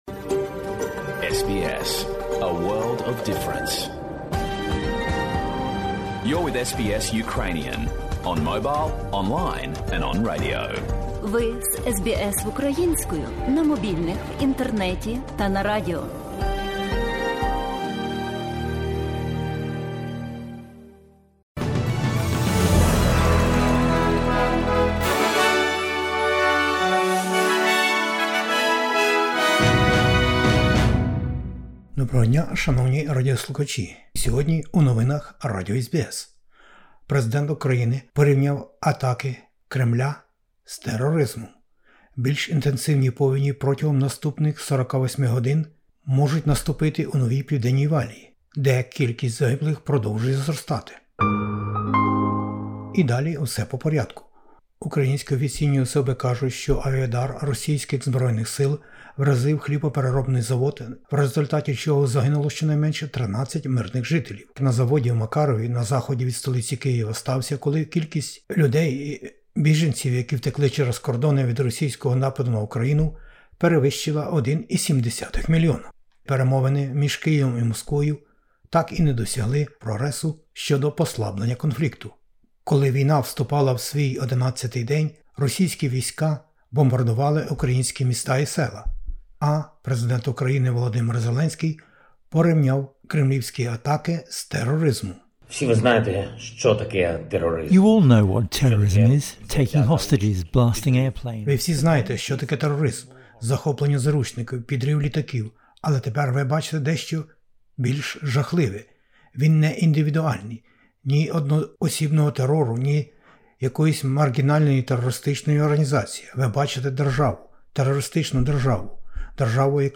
SBS новини українською - 08/03/2022